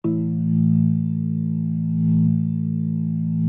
B3LESLIE A 2.wav